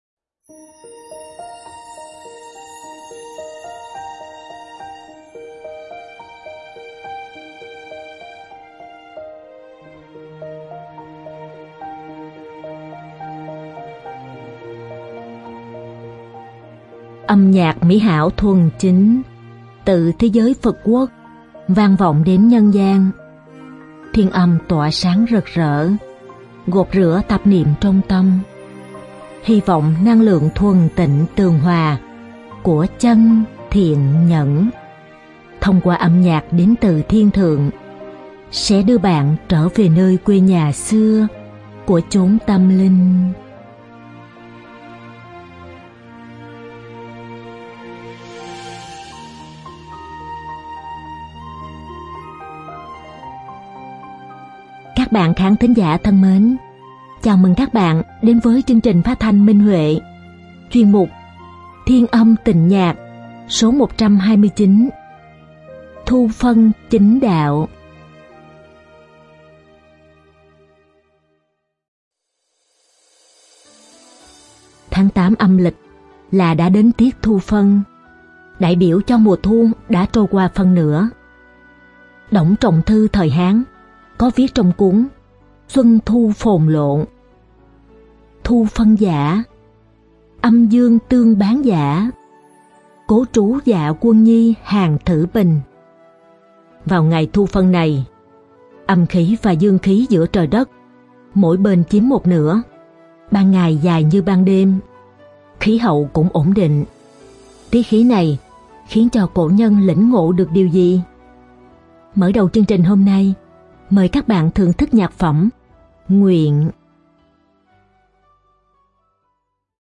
Đơn ca nữ
Đơn ca nam